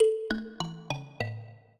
mbira
minuet7-6.wav